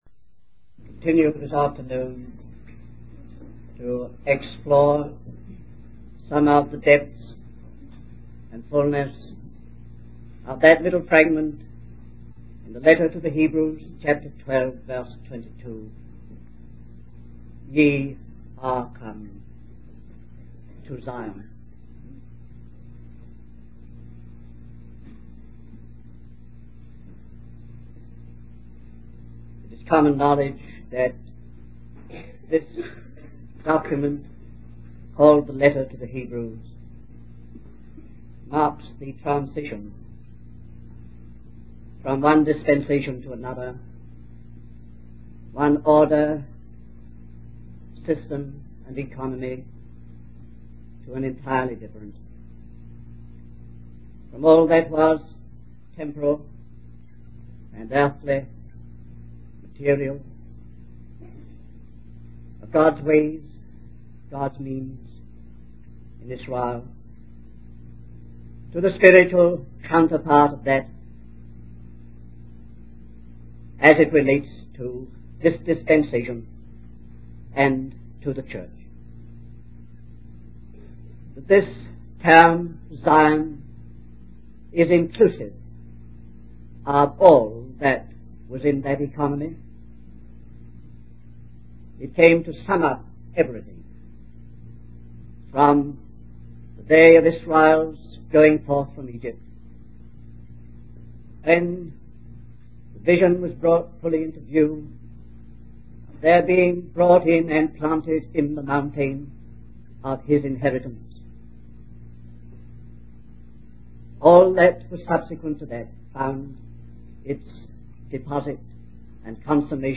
In this sermon, the speaker emphasizes the importance of recognizing and embracing the specific purpose and calling that God has for each individual. He encourages listeners to see their lives as being part of a greater destiny and to live with a sense of purpose and focus.